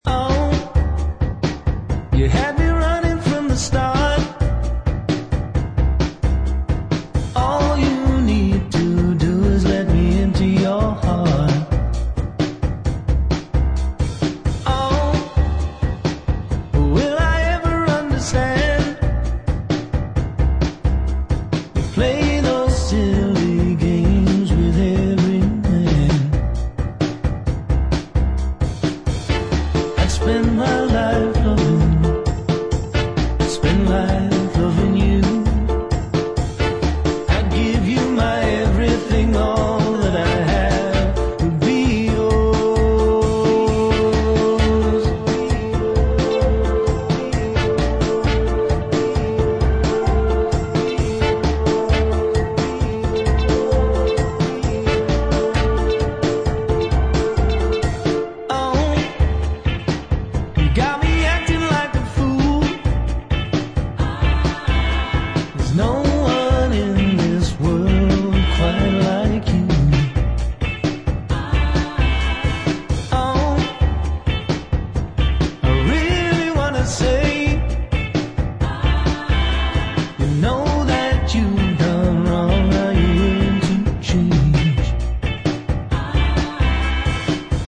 Nice cover version